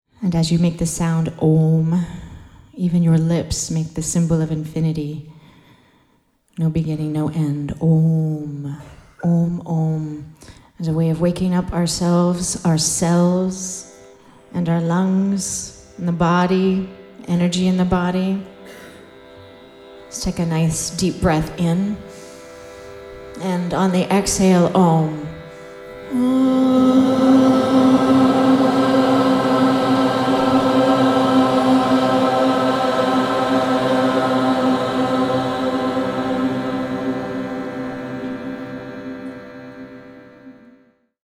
This is a powerful live group chanting of Om.